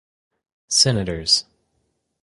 Pronúnciase como (IPA)
/ˈsɛn.ə.tɚz/